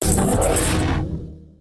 attack3.ogg